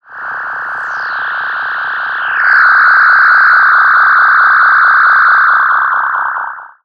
Filtered Feedback 09.wav